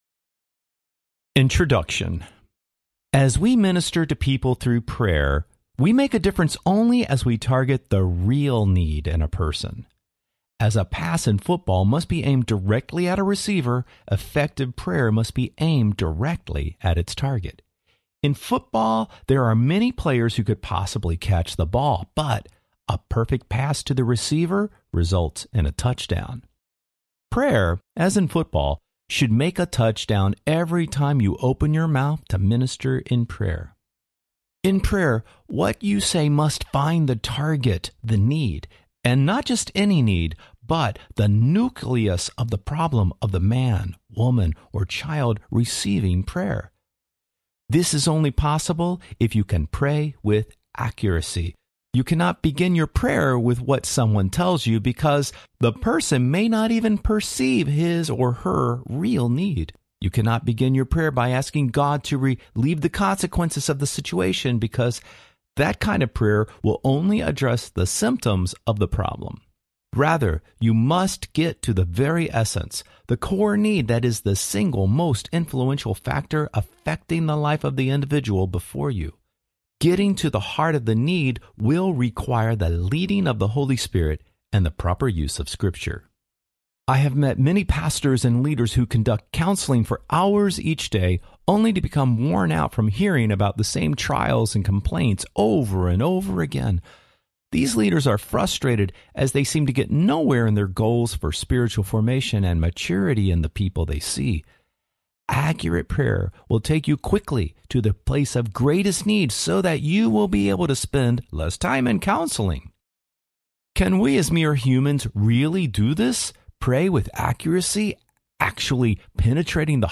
Praying with Accuracy Audiobook
8.6 Hrs. – Unabridged